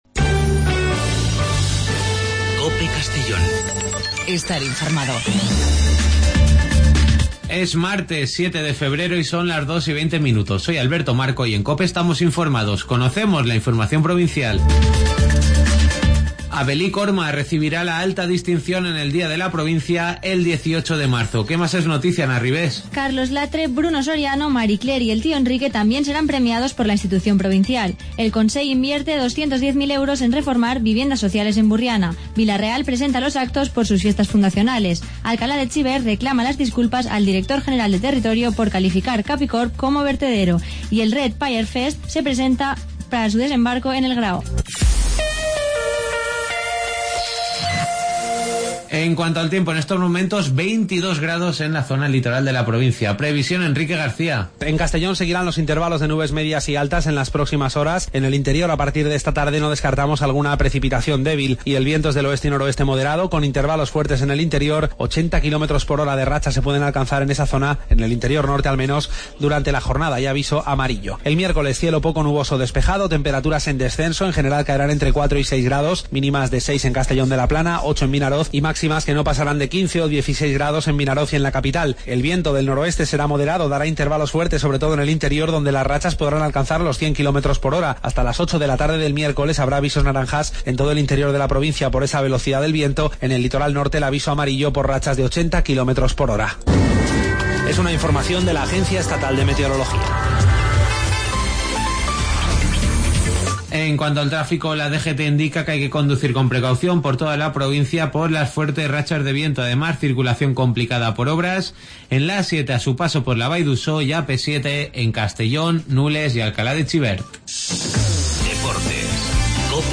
Las noticias del día de 14:20 a 14:30 en Informativo Mediodía COPE en Castellón.